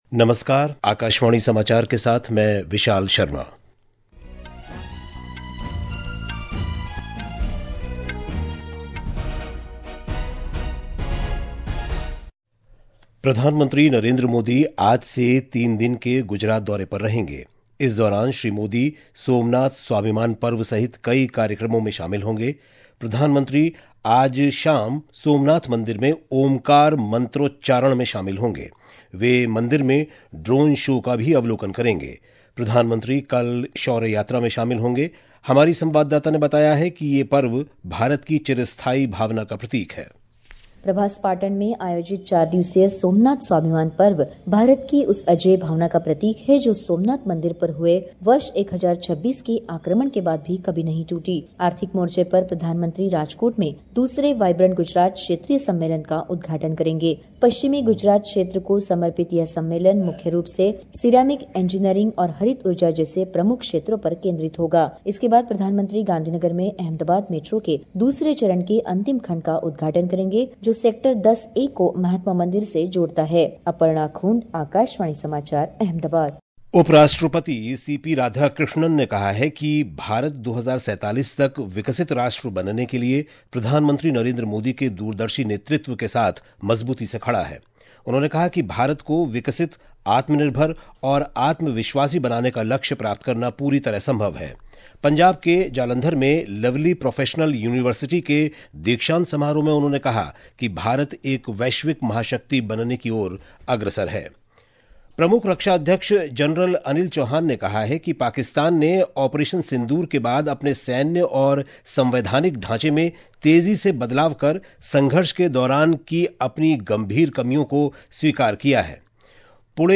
National Bulletins
प्रति घंटा समाचार | Hindi